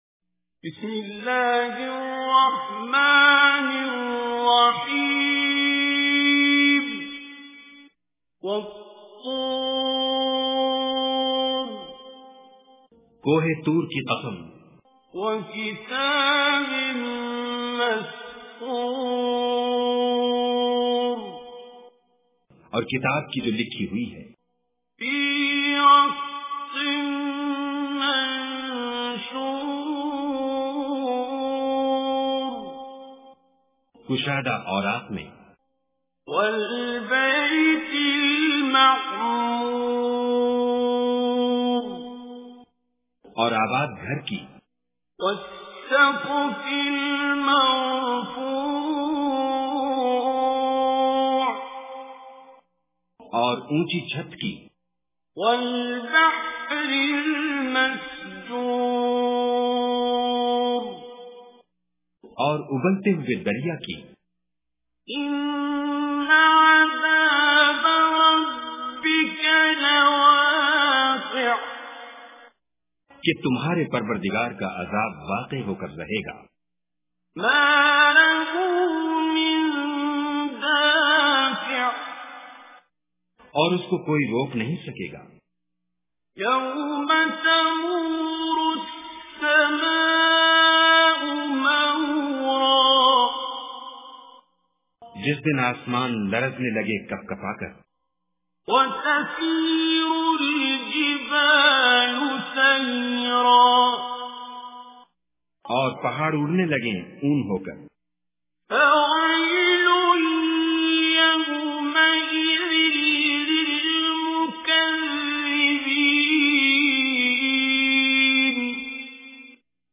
Surah At-Tur is 52nd chapter of Holy Quran. Listen online and download beautiful Quran tilawat / recitation in the beautiful voice of Qari Abdul Basit As Samad.